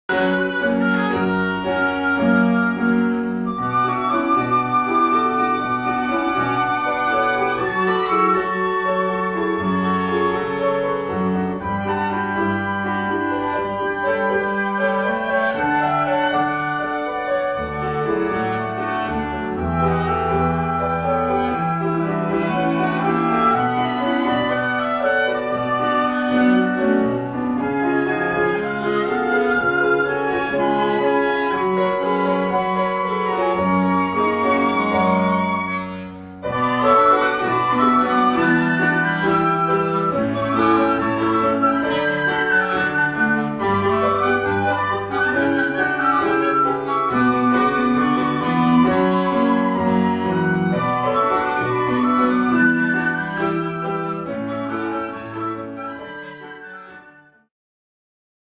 Flute、Oboe、Cello、Piano
（241,440 bytes） フルートとオーボエの美しいハーモニー。